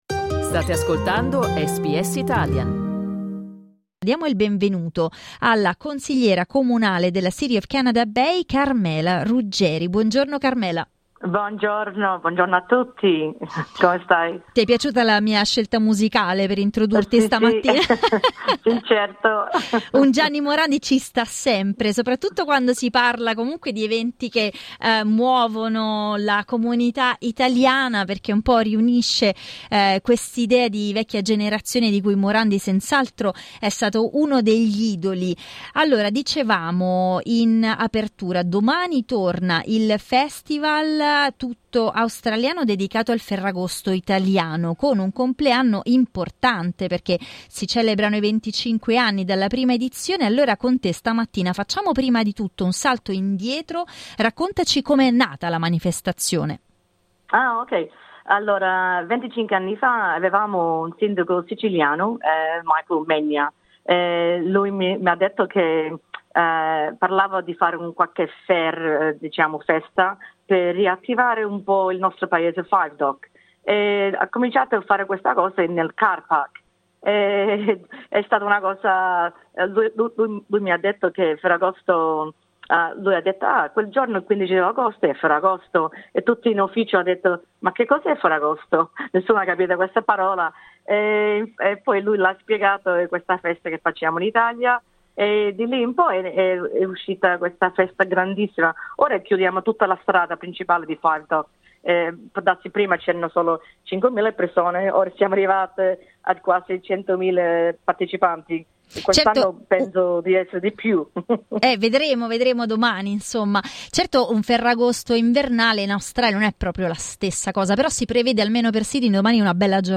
La prima edizione dello 'street party' risale a 25 anni fa, come ha raccontato ai microfoni di SBS Italian Carmela Ruggeri, consigliera comunale della City of Canada Bay che promuove l'evento.